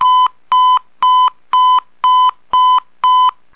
motorola-pageboy_24739.mp3